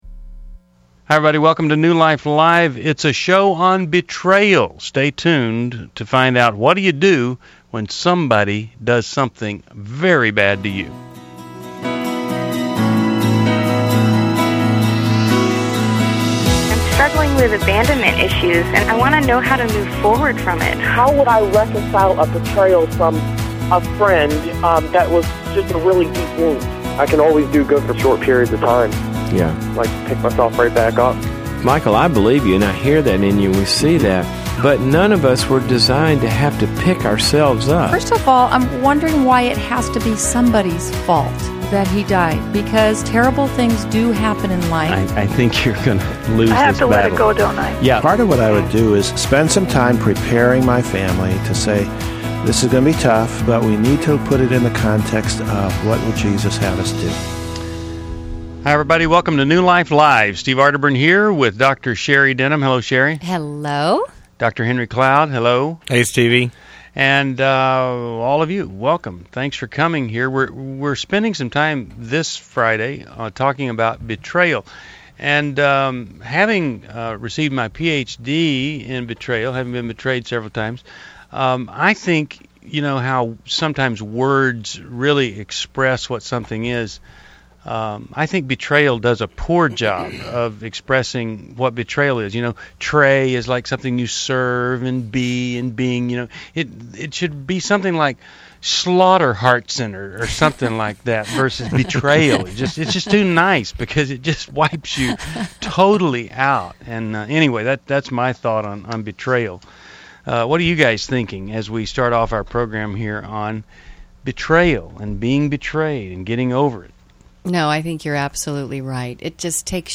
Caller Questions (Focus Friday – Betrayal): 1. My husband had an affair and lied about it; should we continue to work it out? 2. How long does it take to rebuild trust after an affair? 3.